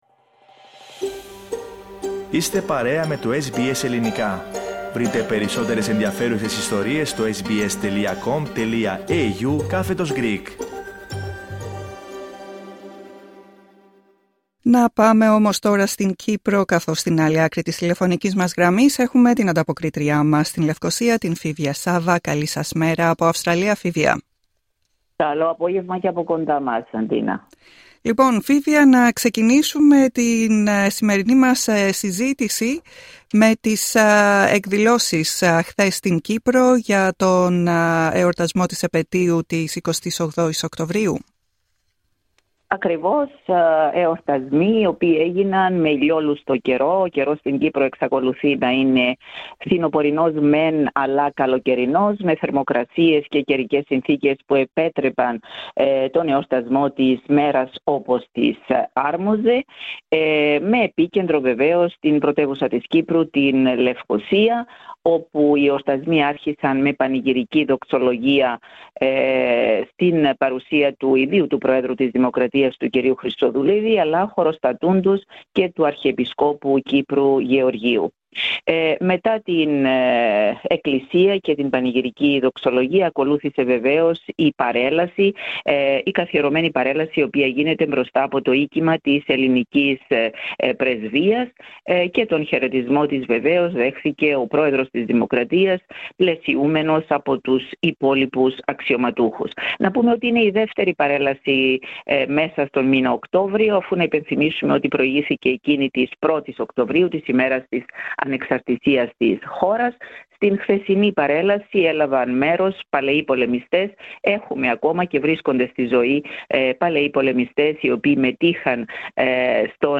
Ανταπόκριση-Κύπρος: Με λαμπρότητα τίμησε χθες η Κύπρος την επέτειο της 28ης Οκτωβρίου